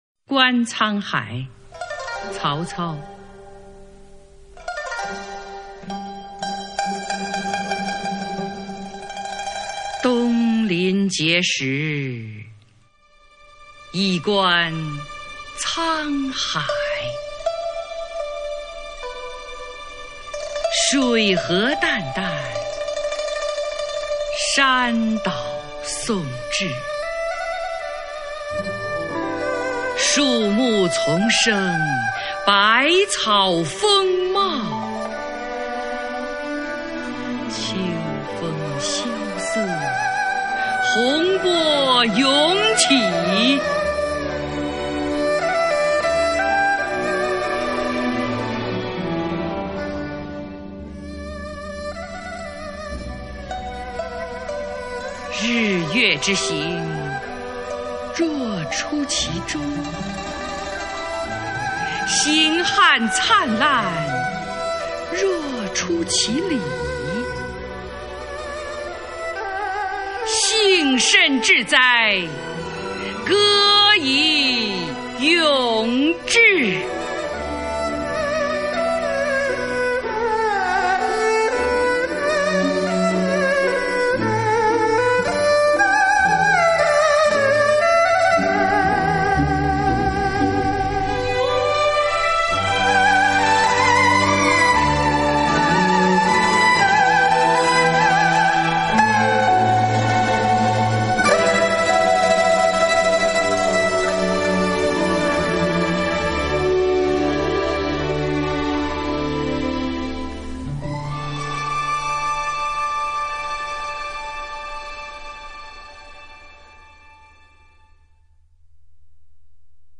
首页 视听 经典朗诵欣赏 群星璀璨：中国古诗词标准朗读（41首）